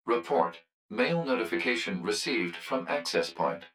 042_Mail_Notification.wav